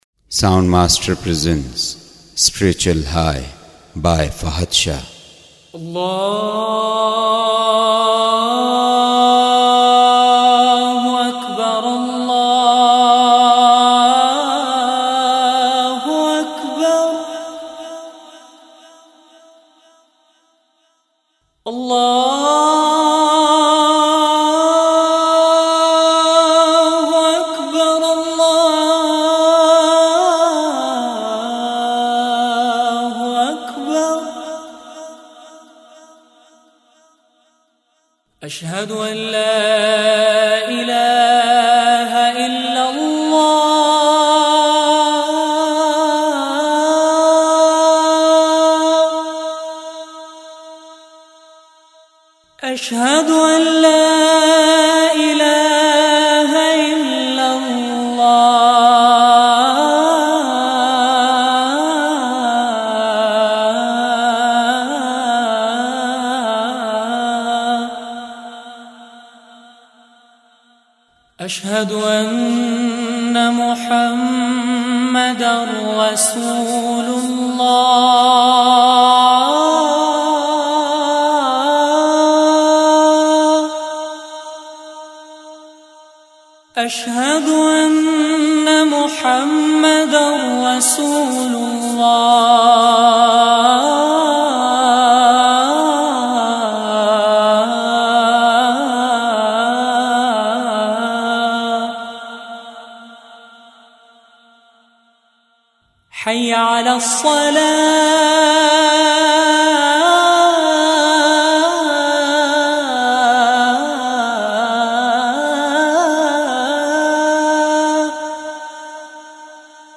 Azan And Dua Listen Online & Download MP3
AzanAndDua.mp3